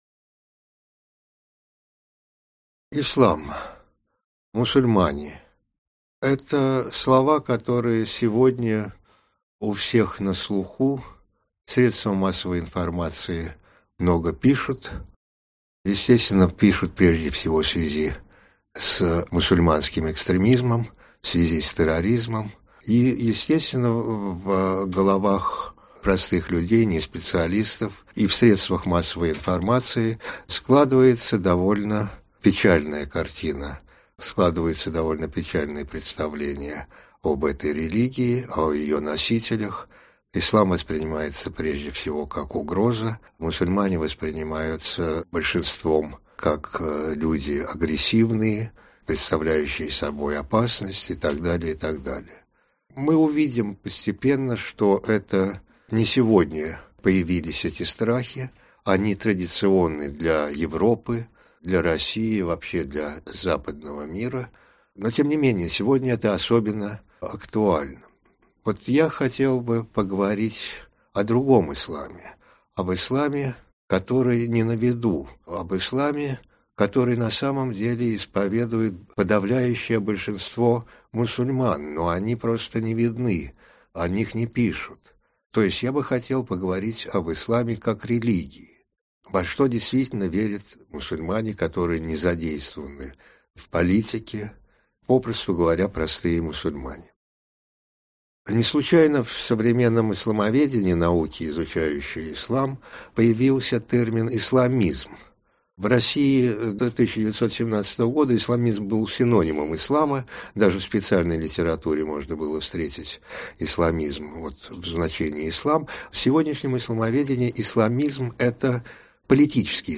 Аудиокнига Введение. Общая характеристика ислама | Библиотека аудиокниг